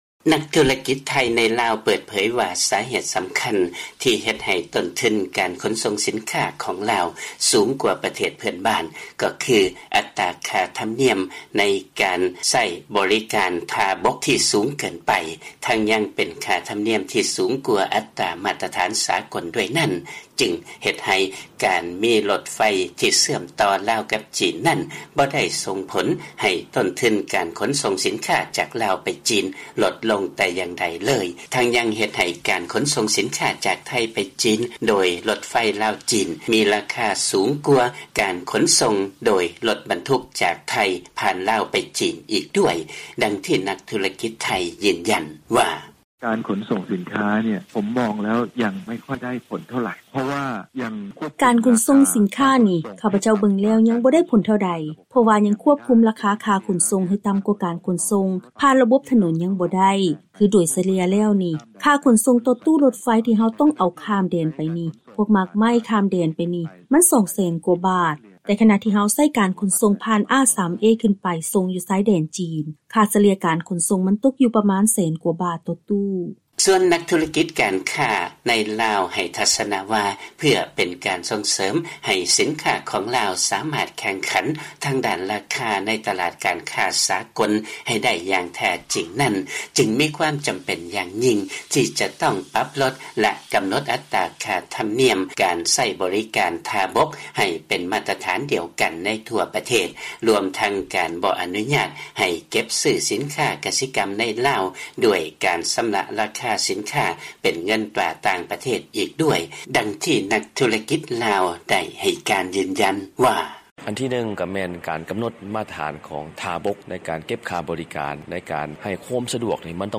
ມີລາຍງານຈາກບາງກອກ.